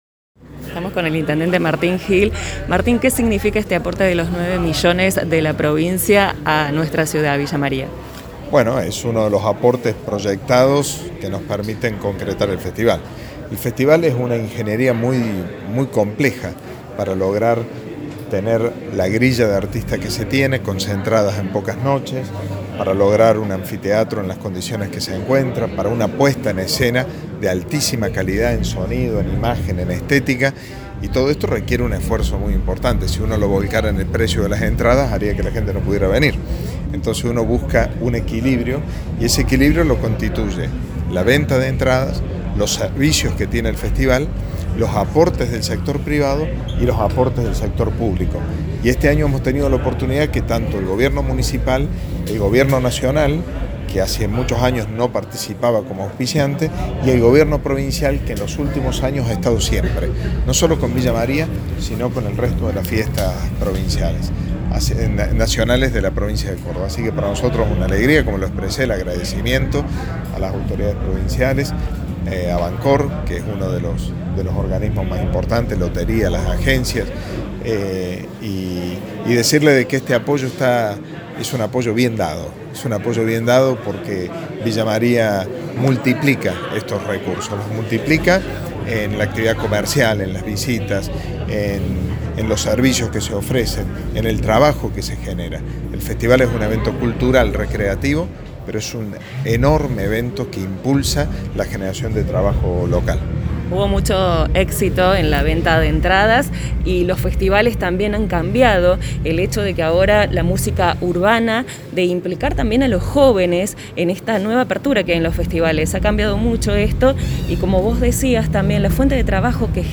En diálogo con RADIO CENTRO, el intendente Martín Gill destacó lo siguiente con respecto al aporte monetario y el inicio del festival esta noche: